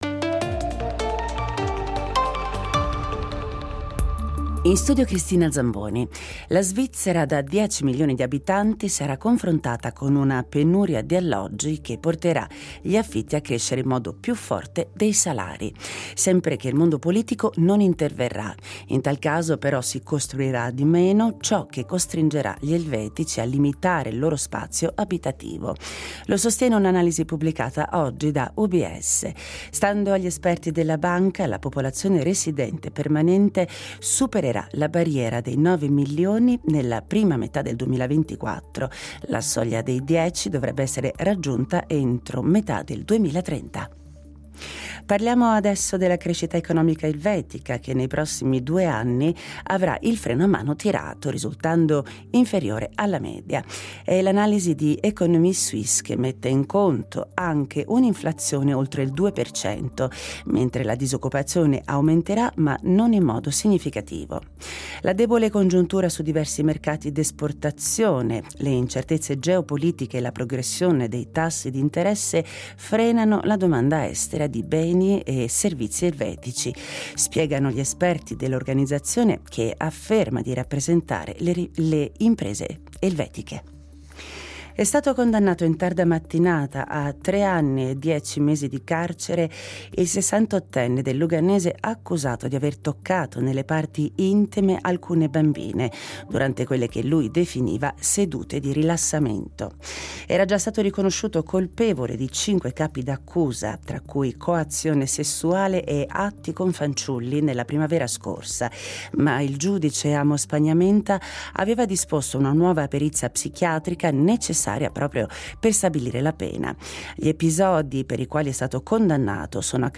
Notiziario